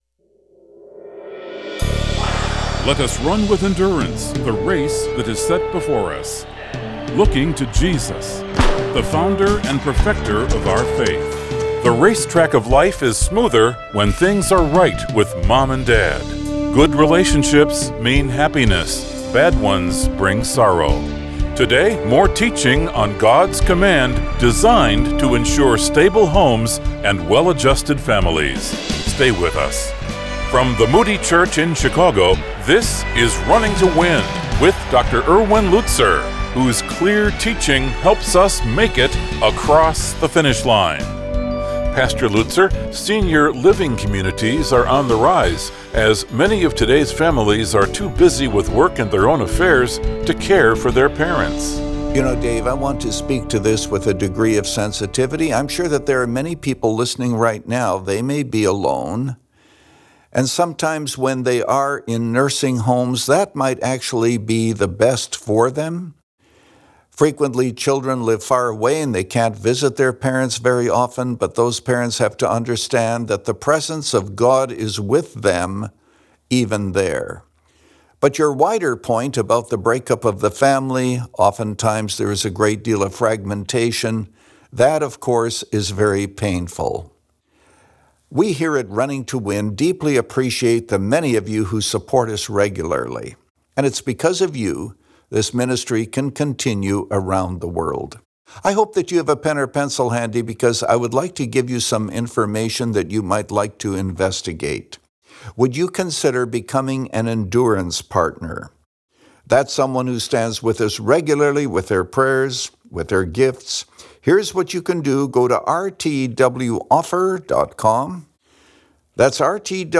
Since 2011, this 25-minute program has provided a Godward focus and features listeners’ questions.